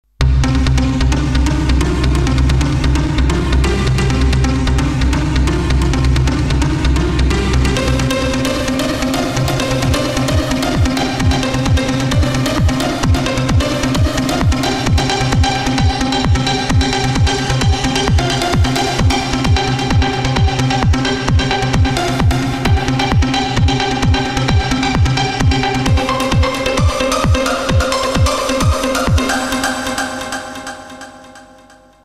MC505 also had similar waveforms, again both from Roland.